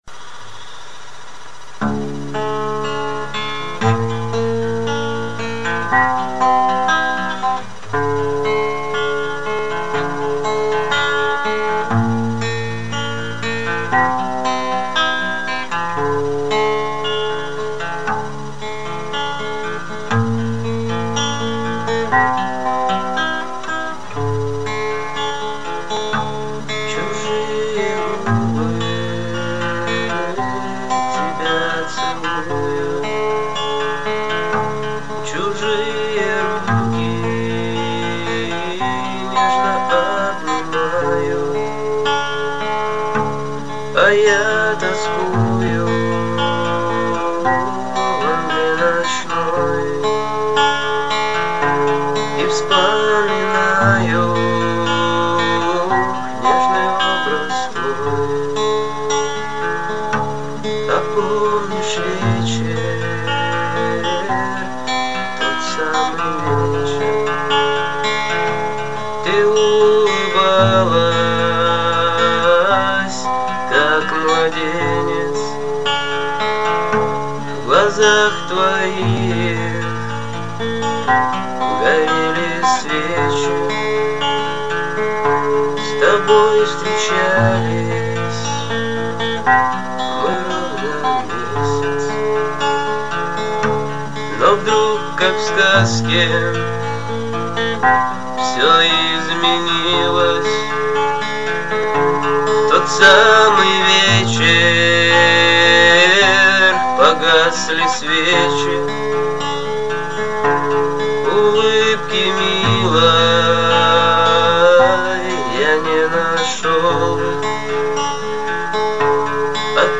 Аккорды Em C D Em играется простым перебором.